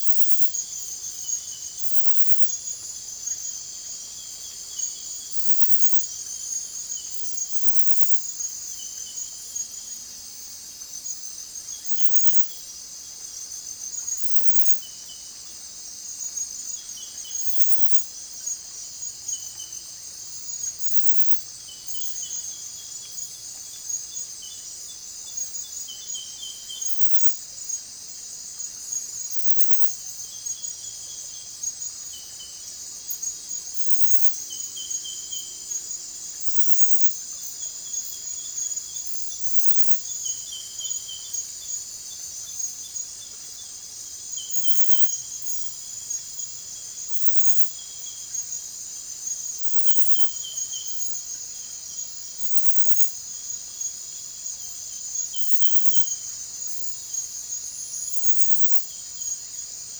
Finals-Data-Ultrasound